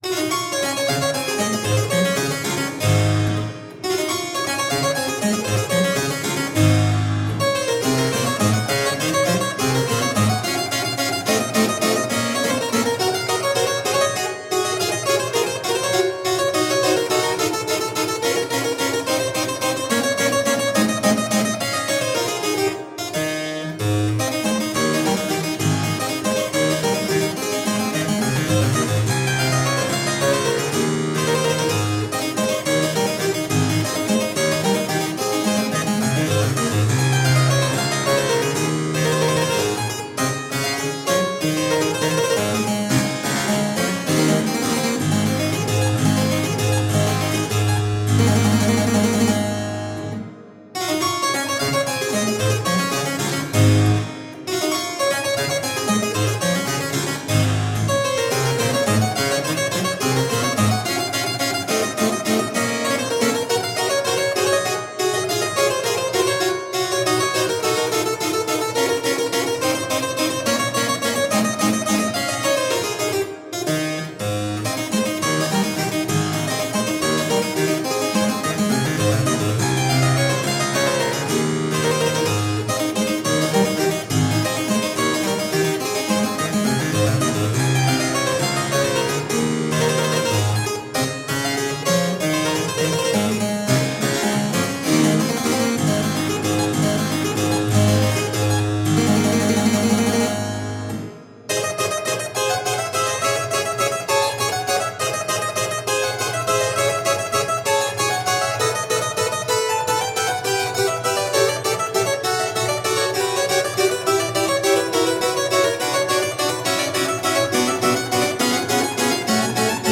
Delicious harpsichord rarities.
Classical, Classical Period, Instrumental, Harpsichord